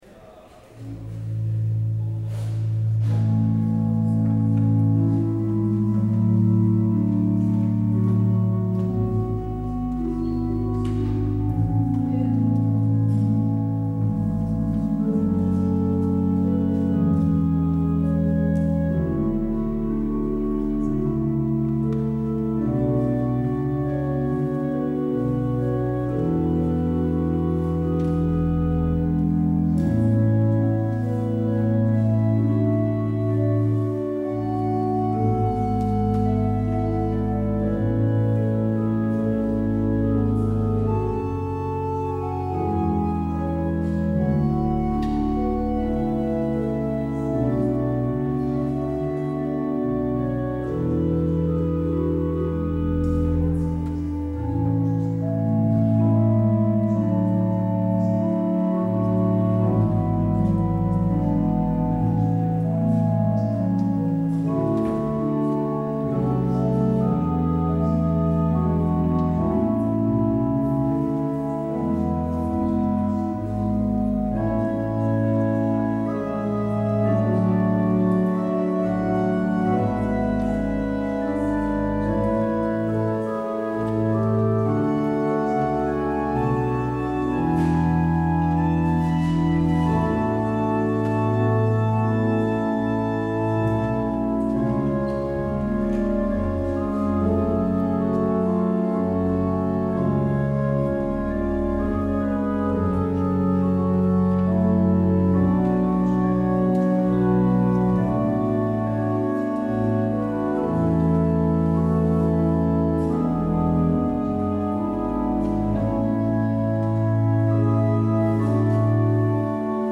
Beluister deze kerkdienst hier: